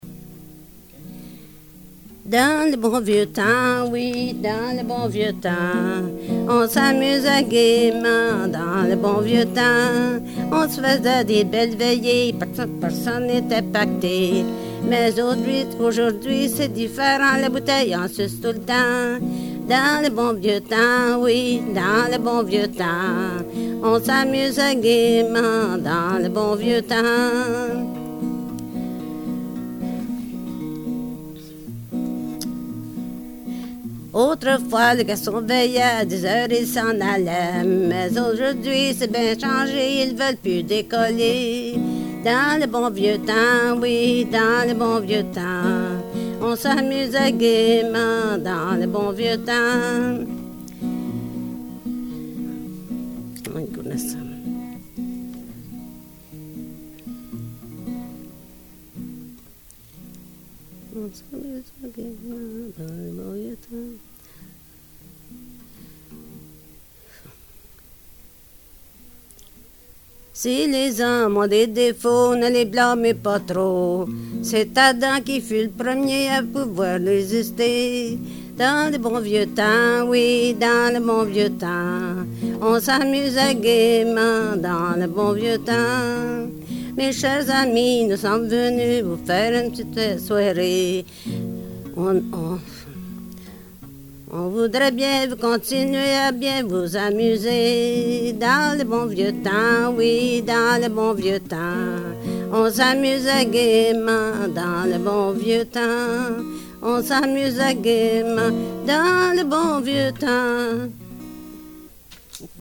Folk Songs, French--New England
sound cassette (analog)